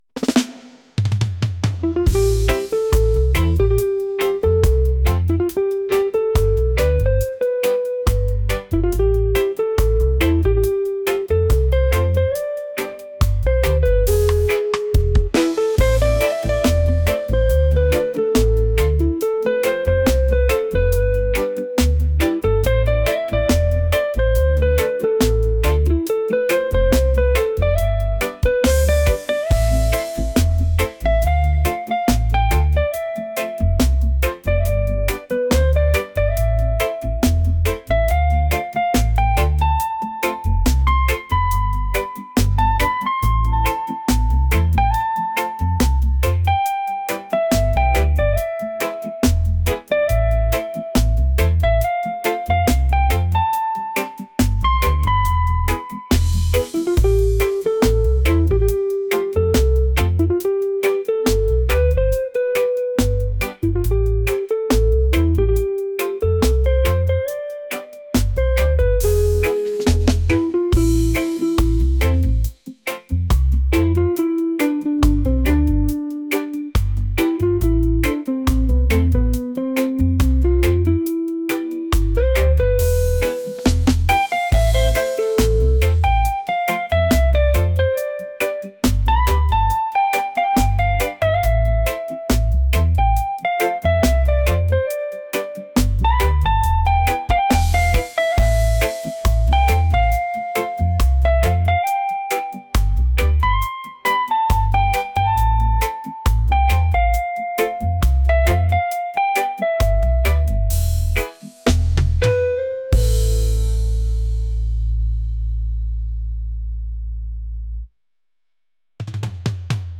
smooth | reggae | romantic